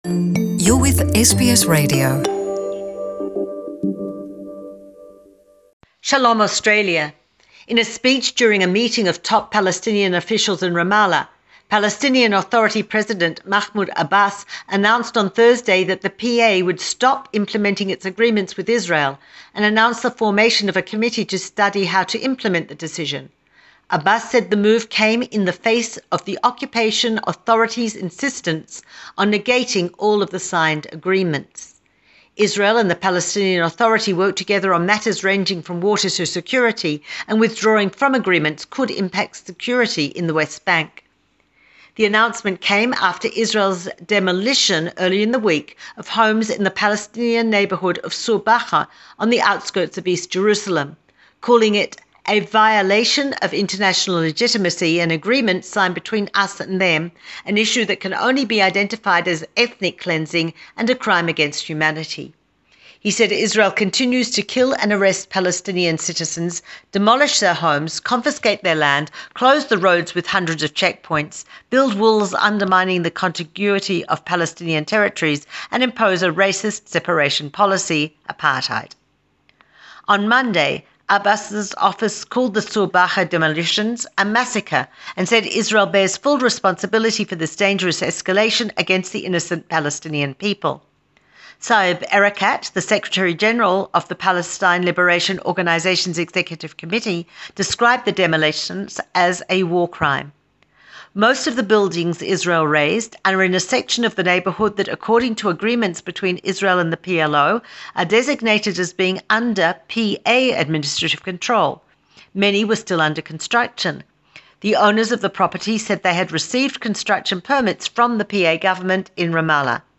News in English as for Sunday, 28.7.19